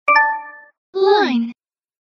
女性の声で「LINE」と通知を教えてくれます。